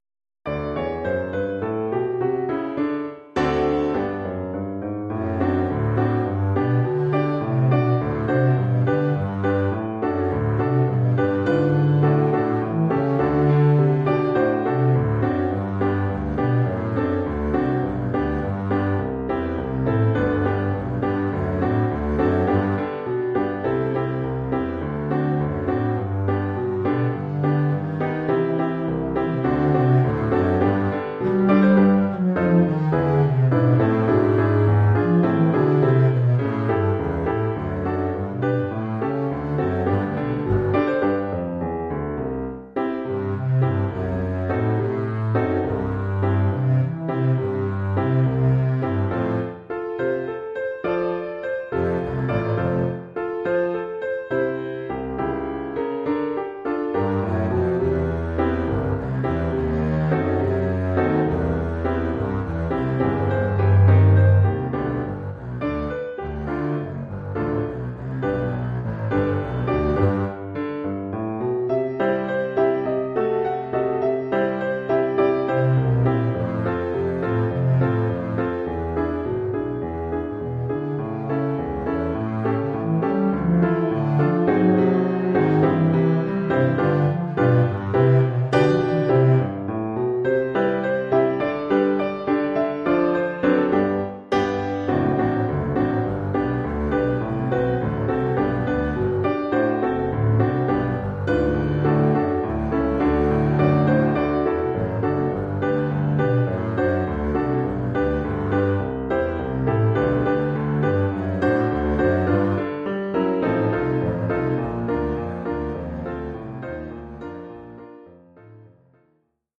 Oeuvre pour contrebasse et piano.